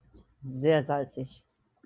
very salty sehr salzig (sehr SAL-zig)